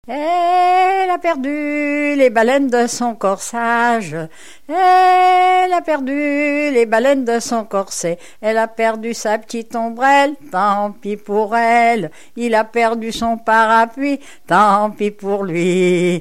sorte de polka
Chants brefs - A danser
Pièce musicale inédite